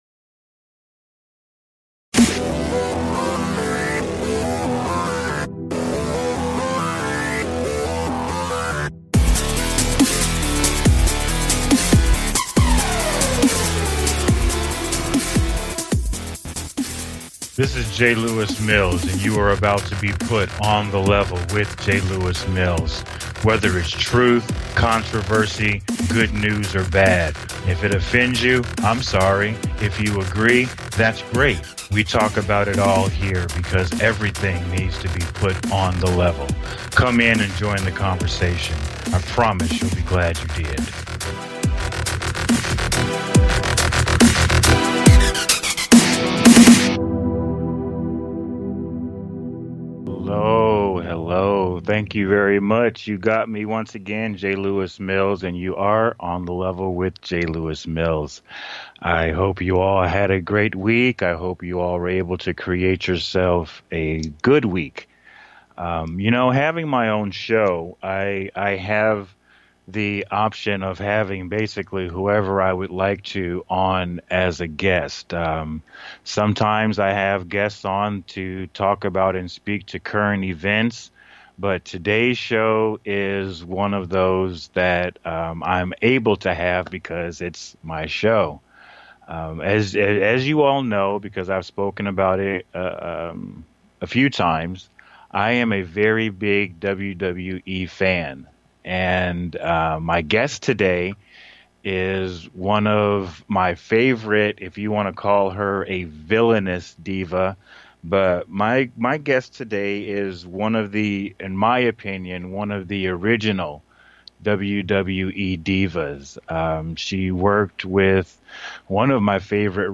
Guest, Amy Weber, recording artist, designer, WWE star